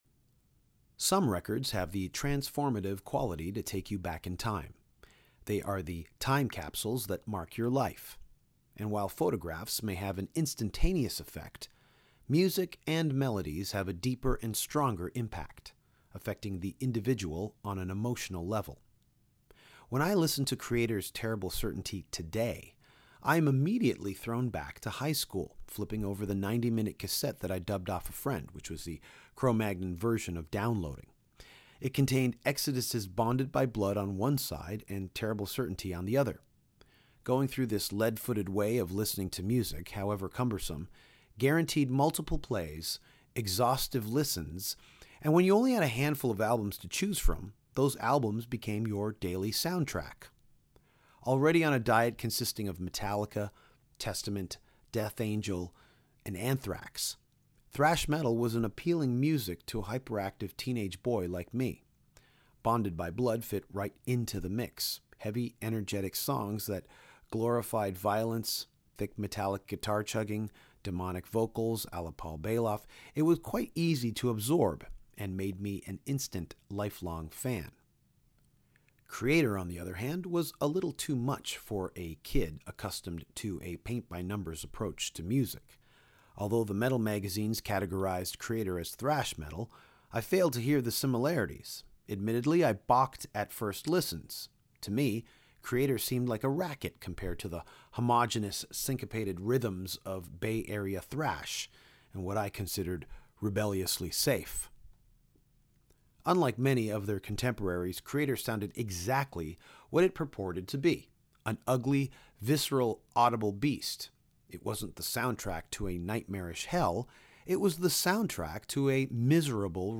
Mille Petrozza, singer/guitarist of the legendary metal band – Kreator, met up with Danko to talk about the first time they met, Metallica, Wacken Open Air Festival, The Scorpions, Rocket From The Crypt, online comments and, of course, Kreator....